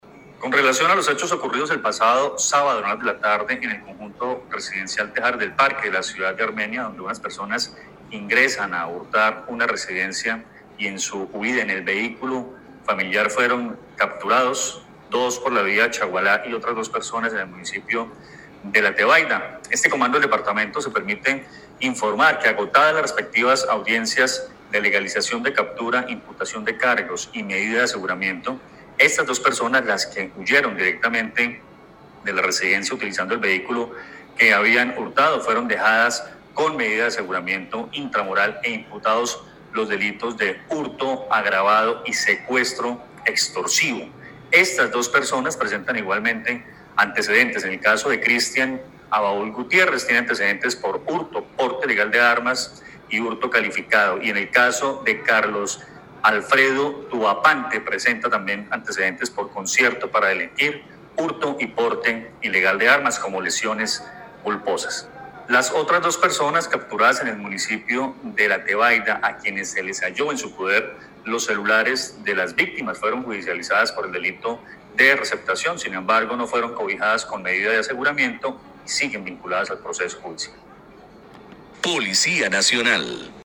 Comandante-Policia-Quindio-y-caso-Tejares-del-Parque.mp3